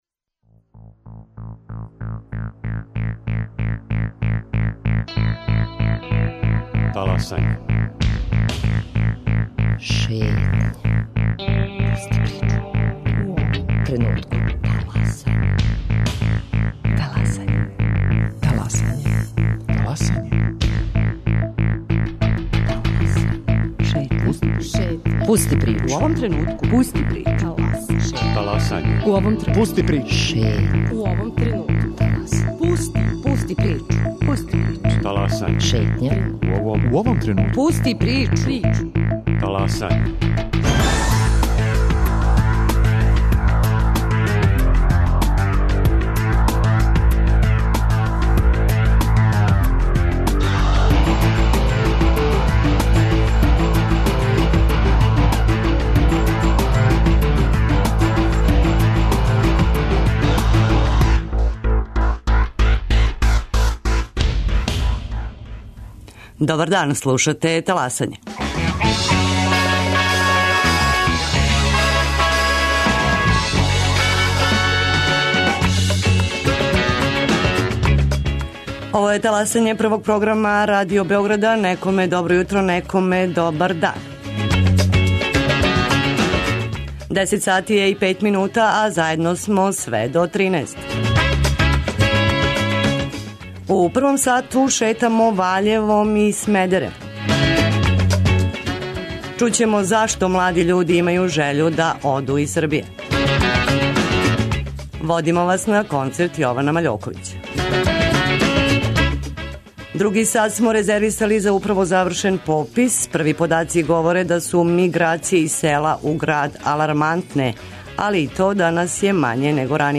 Водимо вас на Смедеревске песничке јесени , прошетаћемо Ваљевом, а преносимо и атмосферу са концерта Јована Маљоковића.